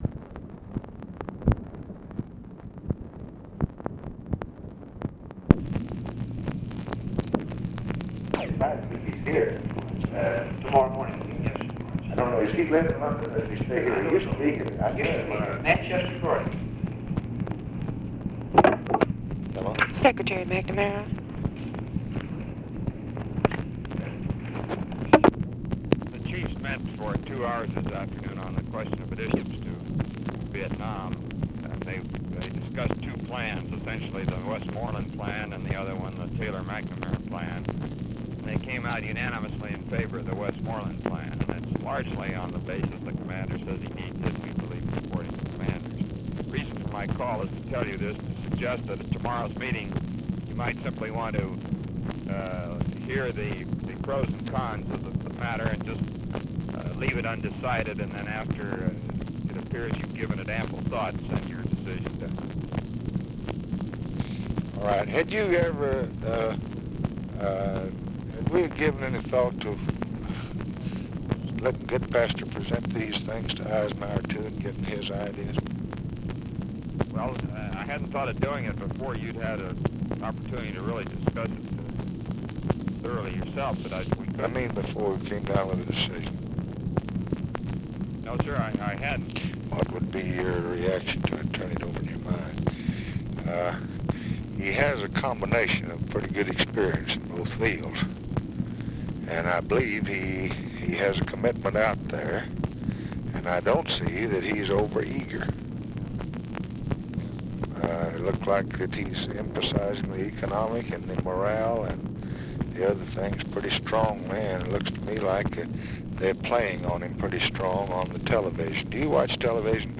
Conversation with ROBERT MCNAMARA and OFFICE CONVERSATION, June 10, 1965
Secret White House Tapes